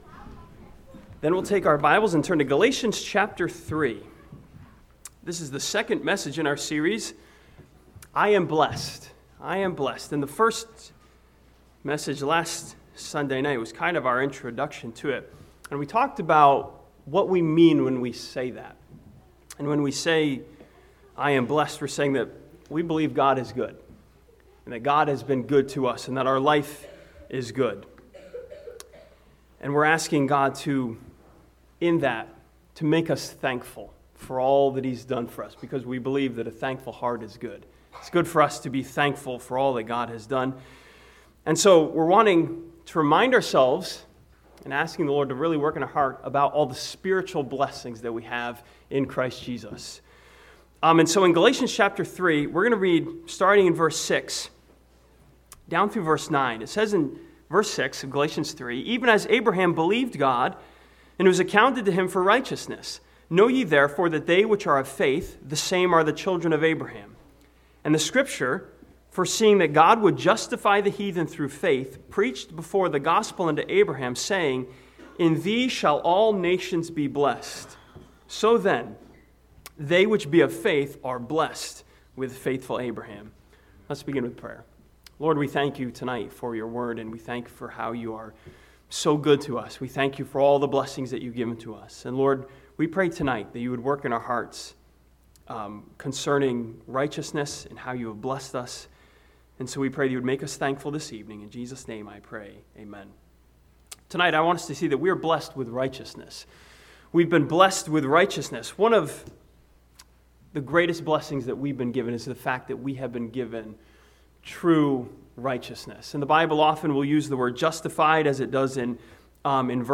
This sermon from Galatians chapter 3 presents believers with the glorious truth that we have been blessed with righteousness.